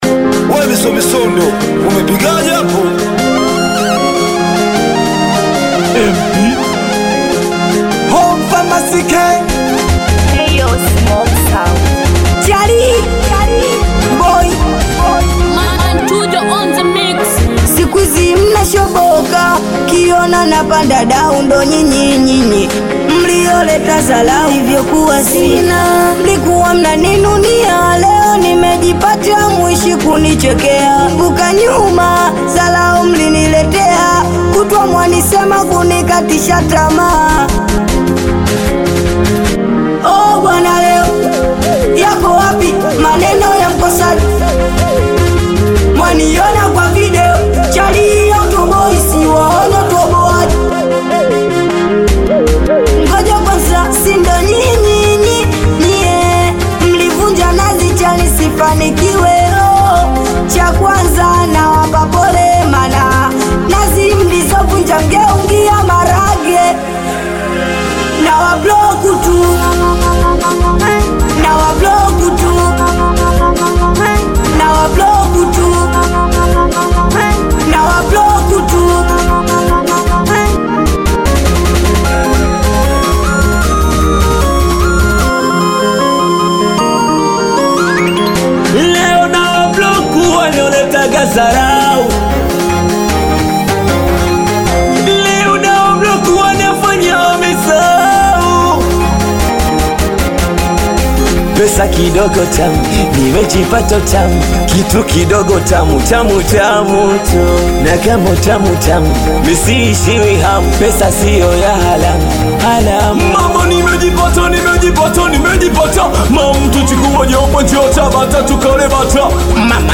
Tanzanian Bongo Flava Singeli
Singeli